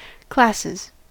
classes: Wikimedia Commons US English Pronunciations
En-us-classes.WAV